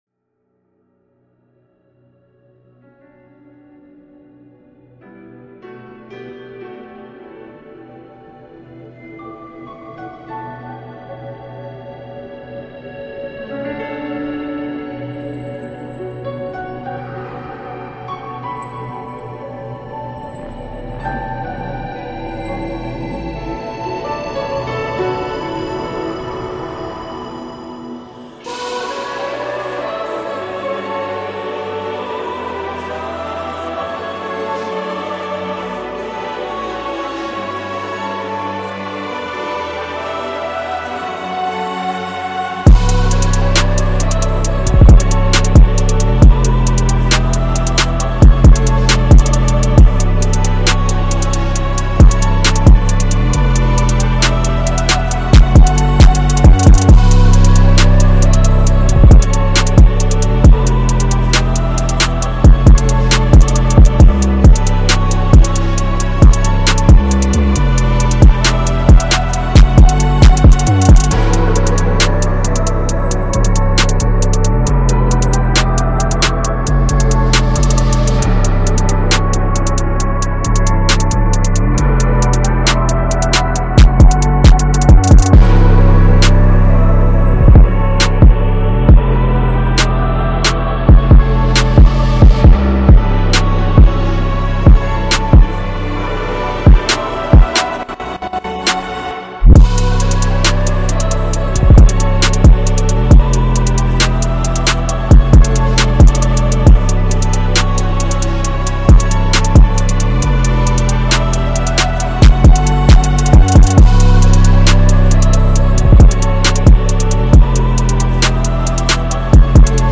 دانلود آهنگ آرامشی بیس دار مخصوص باشگاه